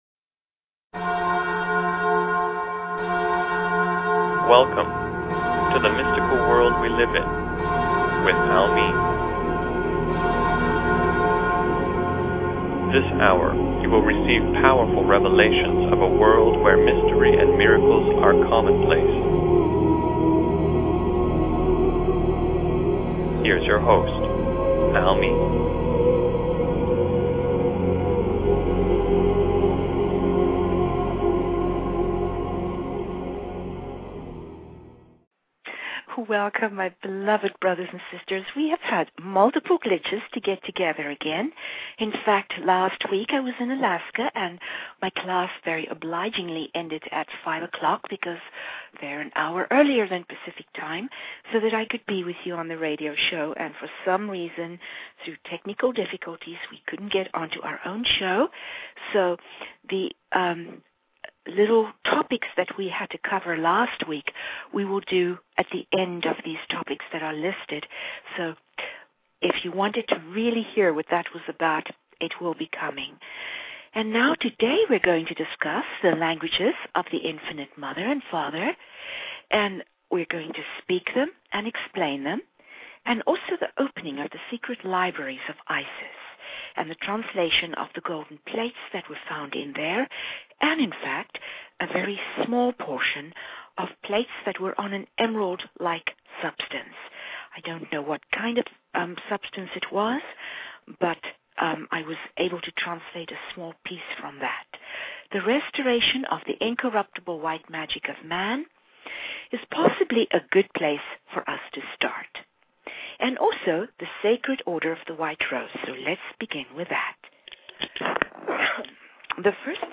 Talk Show Episode, Audio Podcast, The_Mystical_World_we_live_in and Courtesy of BBS Radio on , show guests , about , categorized as